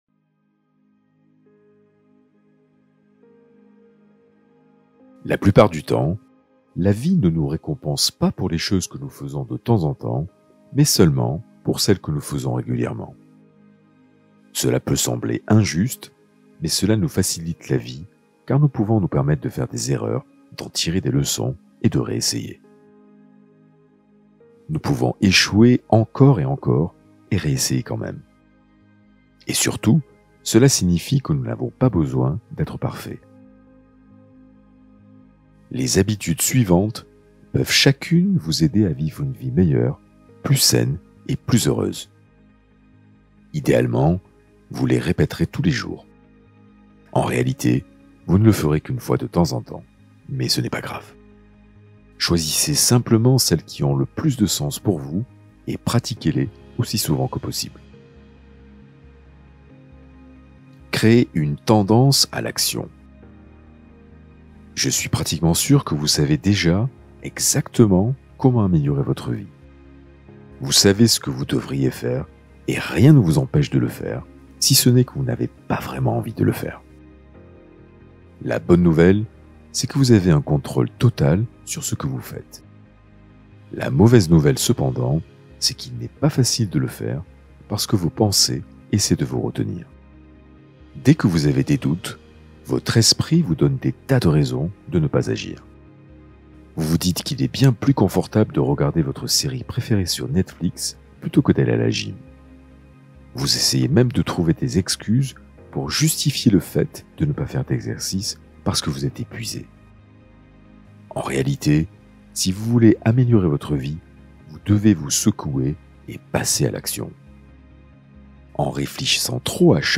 Hypnose pour renforcer l’optimisme et l’énergie positive